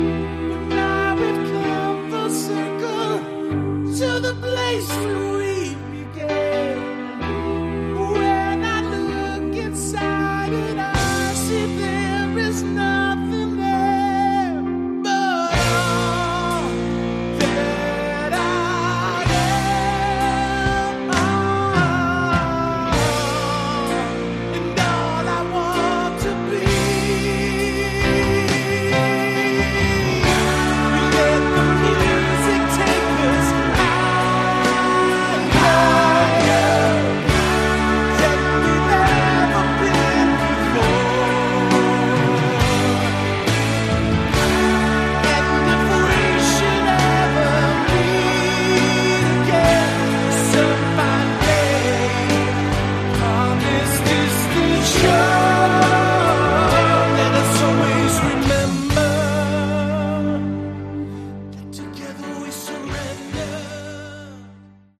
Category: AOR
vocals, guitar, bass
keyboards, vocals
drums